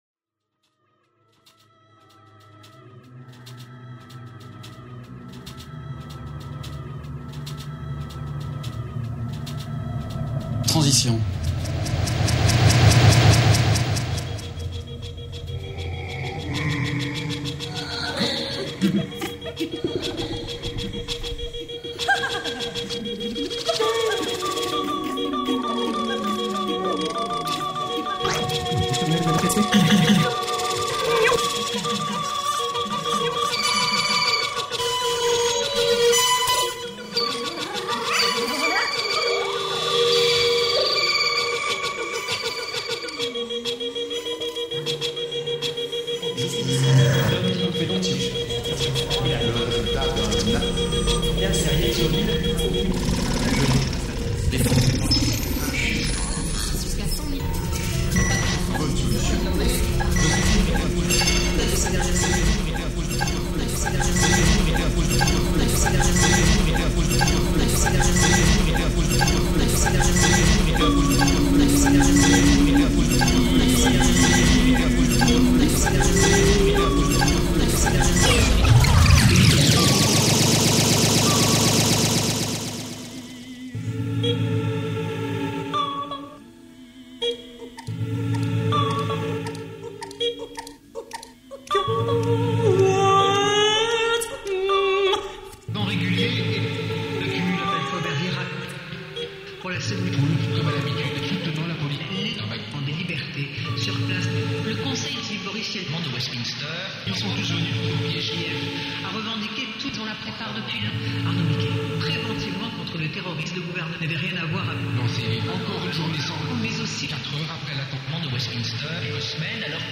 live pour